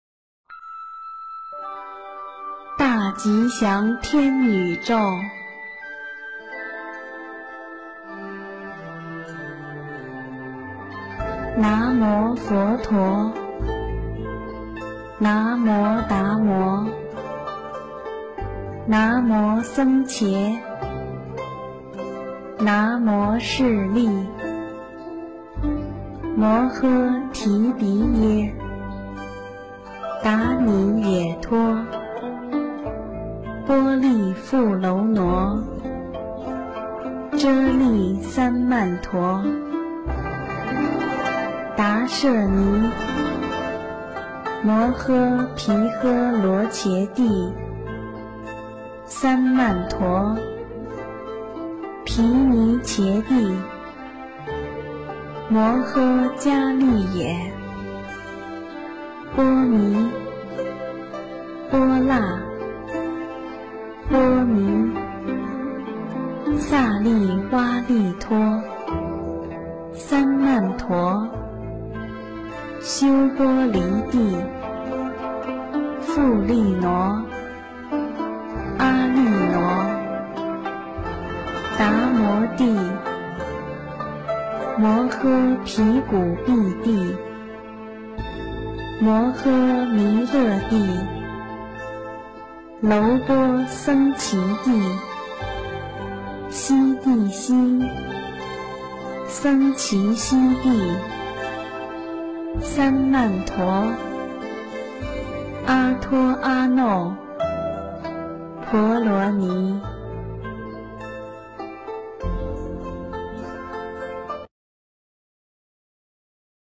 《大吉祥天女咒》英文·最美大字拼音经文教念